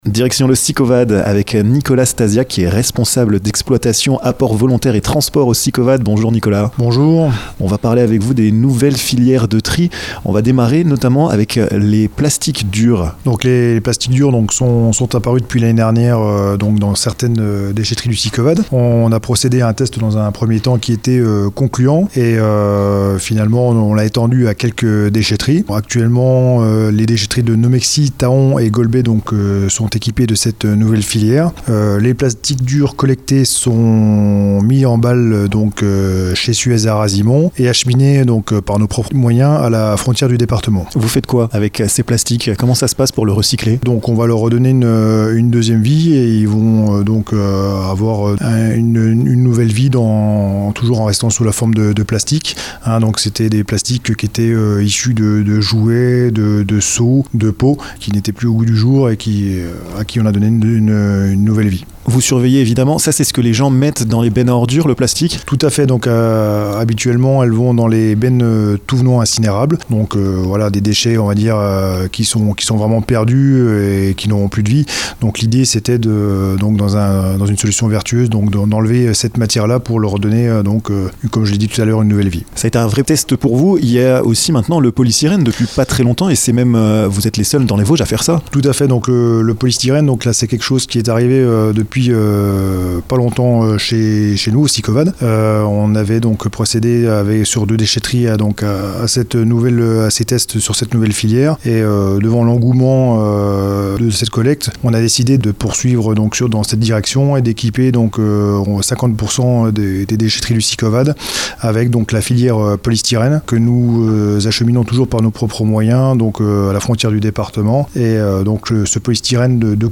%%La rédaction de Vosges FM vous propose l'ensemble de ces reportages dans les Vosges%%
interview